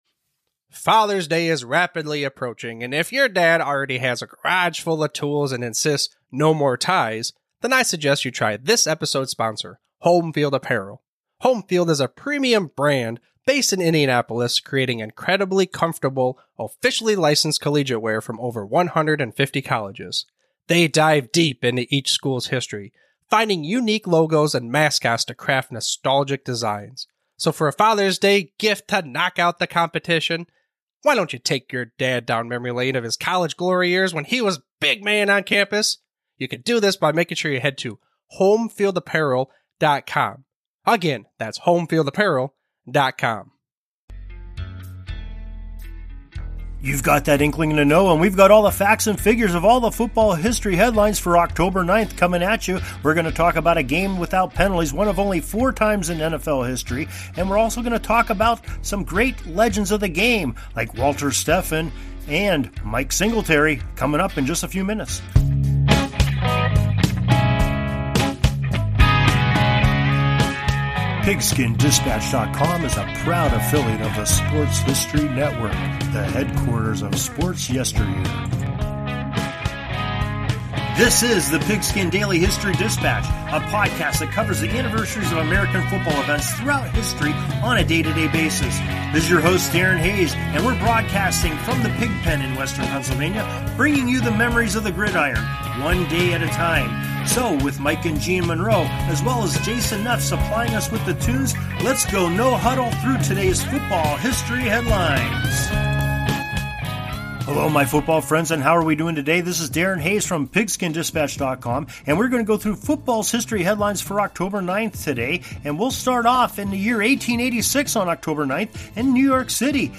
We also feature great music